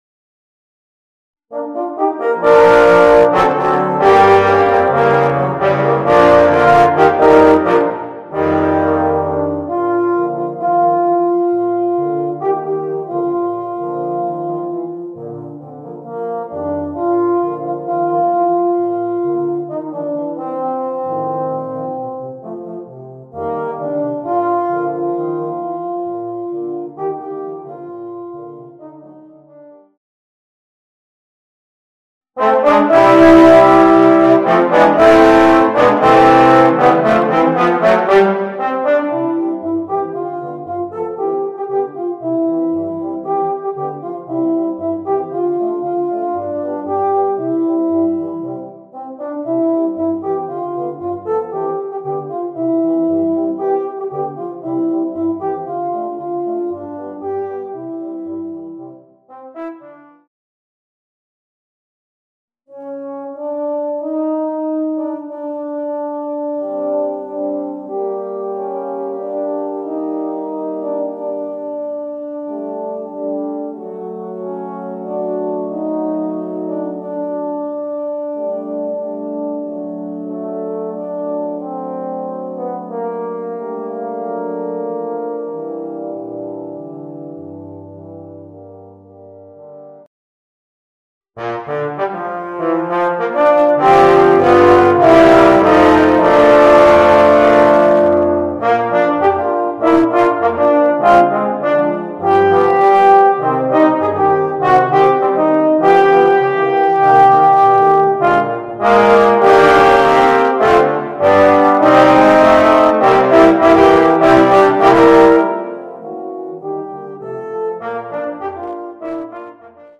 Voicing: 4 Euphoniums